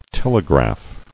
[tel·e·graph]